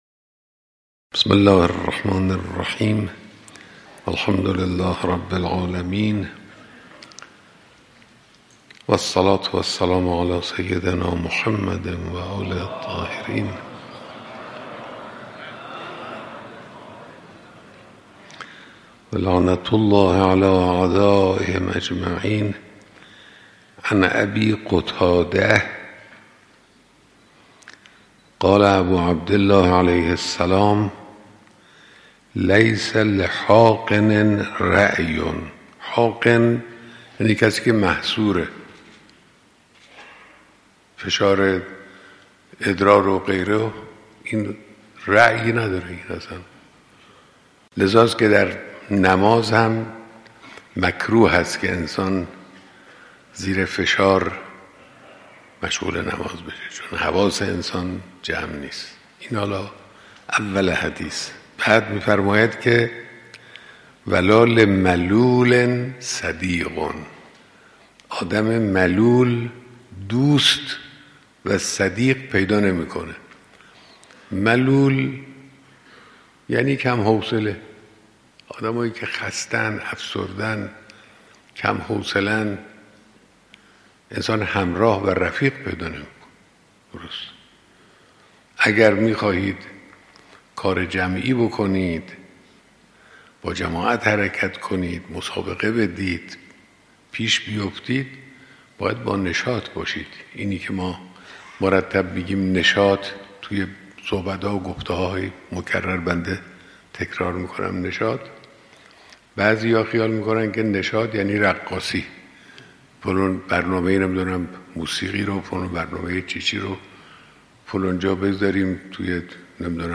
صوت/درس خارج فقه رهبر/معنی نشاط